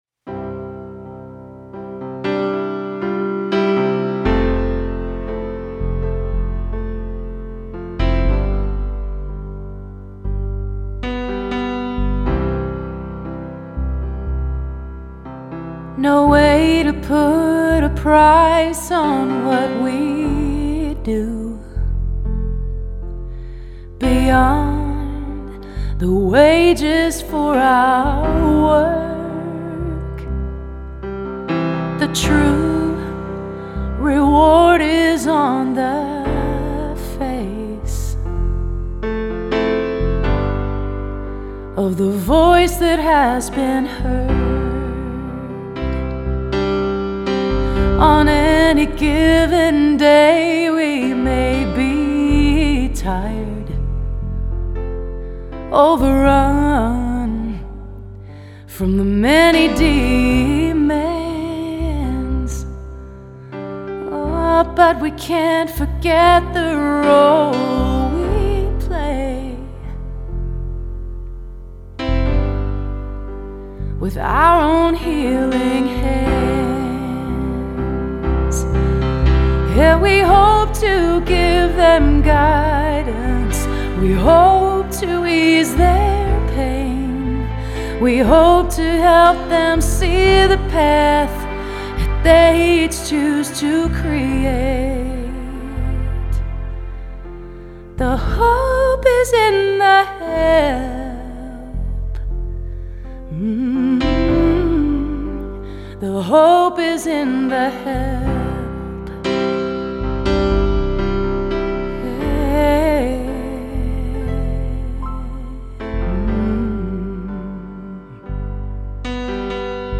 mixed and mastered